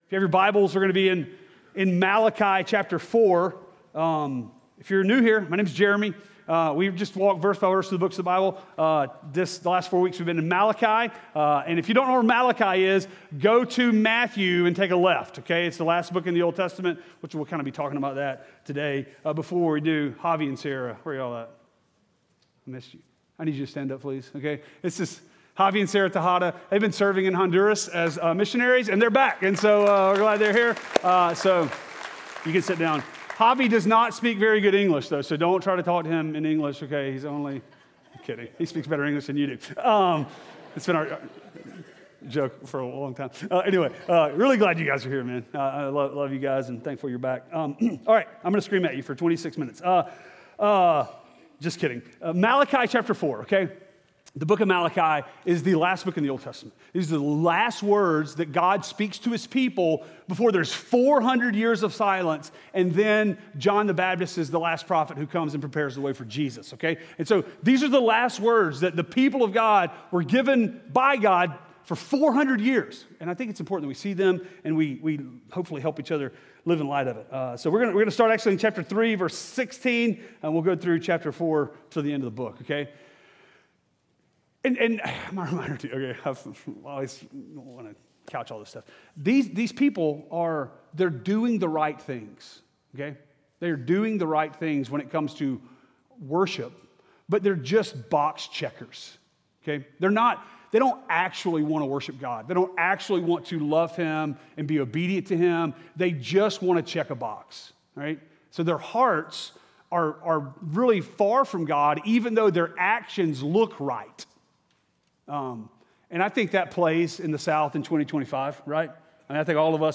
Sermons Malachi Loving The God That Doesn't Change!